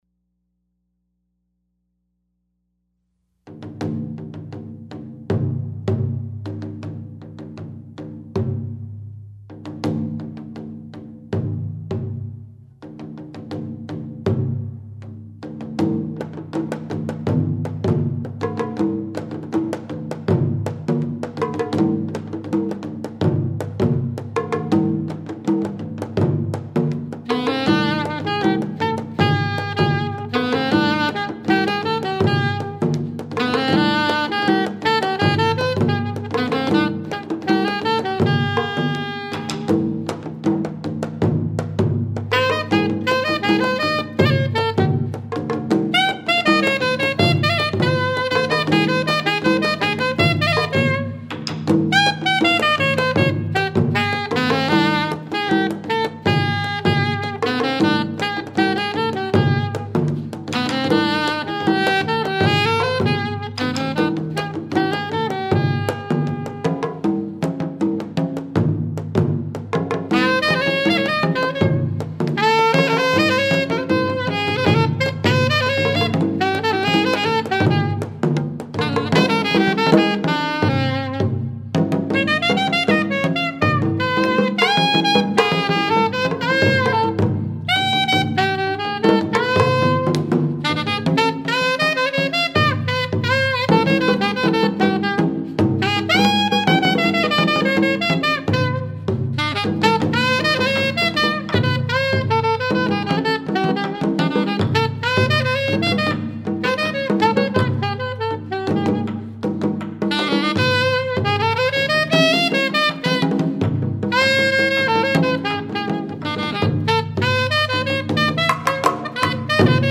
taiko, percussion, alto saxophone, fue, voice